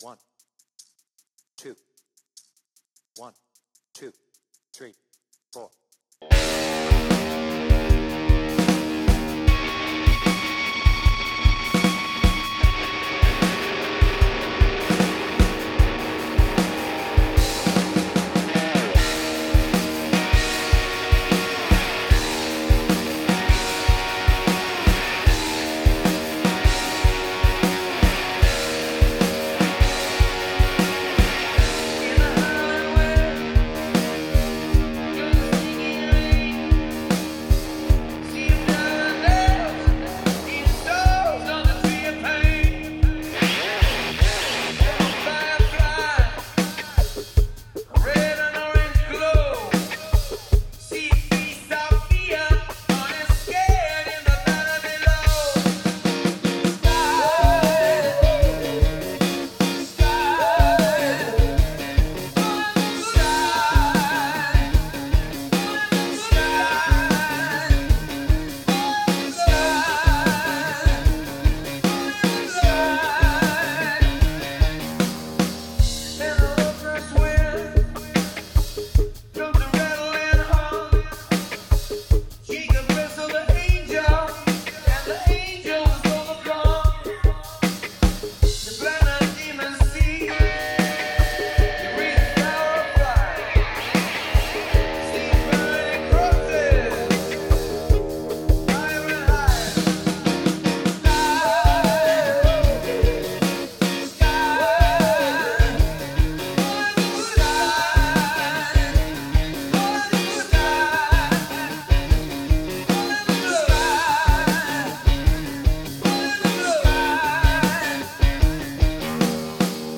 BPM : 76
Tuning : Eb
two solo's on guitar